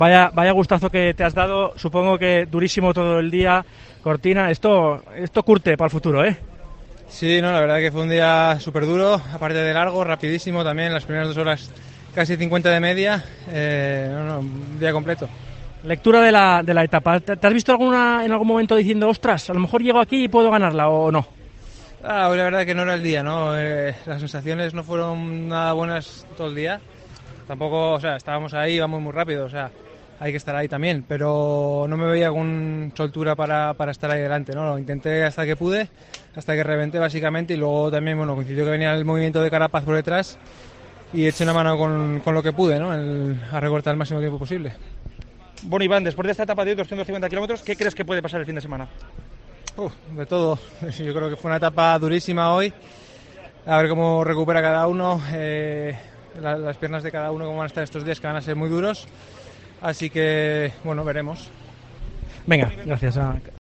El ciclista del Movistar habla con los medios al término de la locura de la 7ª etapa: "Ha sido una etapa durísima hoy, a ver cómo recupera cada uno".